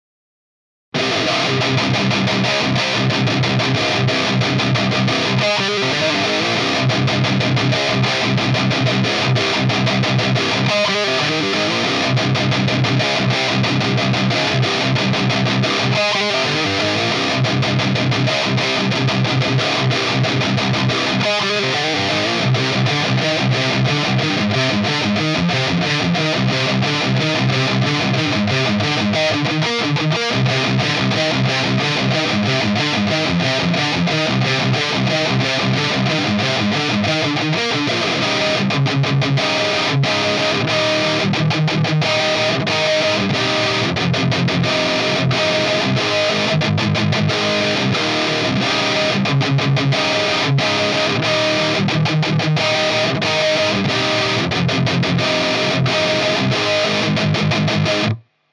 C'est un micro qui a une courbe caractéristique et qui FYZZ car il a une bosse marqué là où un recto FYZZ.
Ça colore pas mal la prise de son, et de par sa technologie, il est imprécis dans l'aigüe.
Pour donner une idée à ceux qui ne maitrise pas forcément, voici des samples enregistré avec un SM57 :
C'est le 57 qui est très/trop brillant.
On a l'impression que la prise avec le 57 a plus de gain.